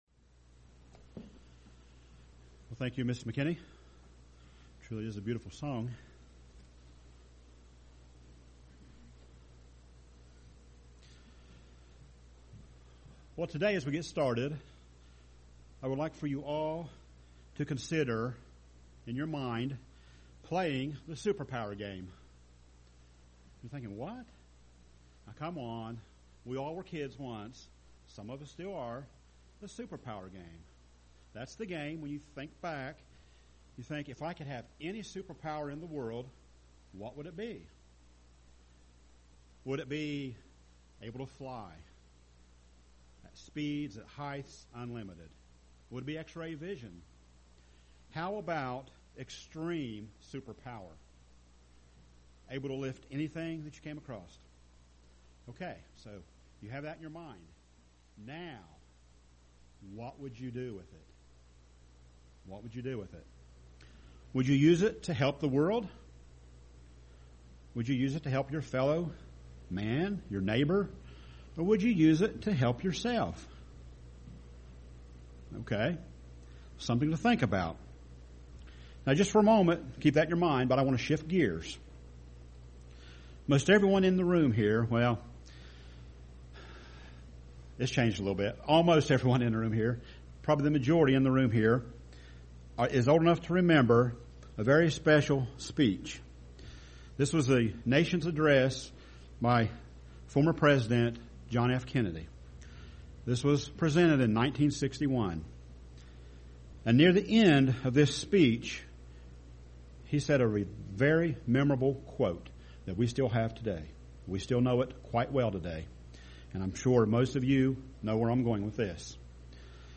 Given in Portsmouth, OH
UCG Sermon Studying the bible?